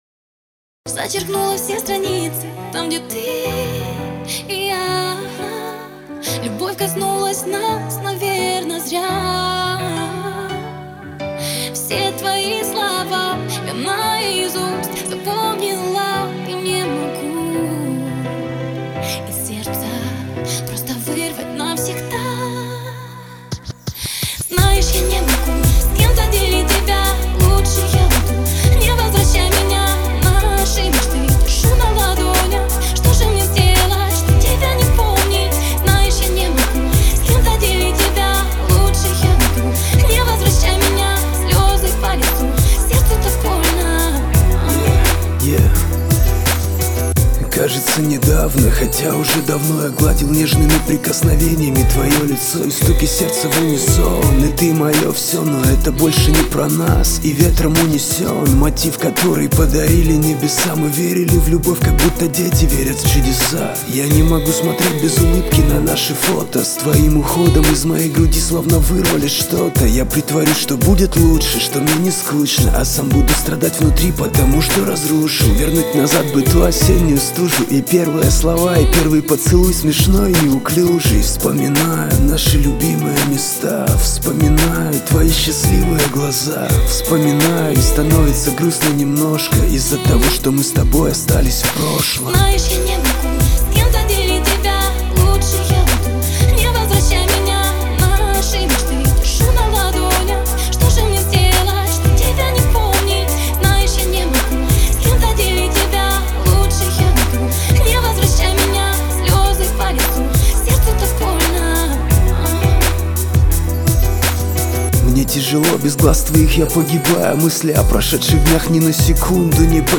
Категория: RAP, R&B